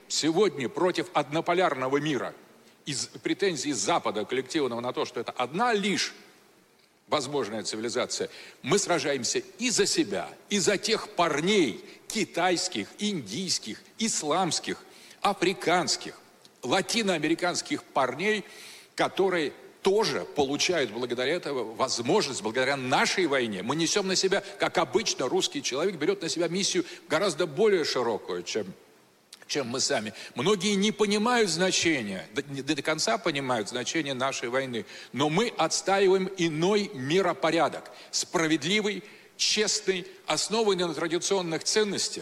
Мы сражаемся и за себя, и за тех китайских, индийских, исламских, африканских и латиноамериканских парней Философ Александр Дугин на форуме Знание.Государство в Национальном центре "Россия" рассказал о решающей роли России в борьбе за многополярный мир и противодействии однополярной системе, которую навязывает Запад: Сегодня против однополярного мира из-за претензии Запада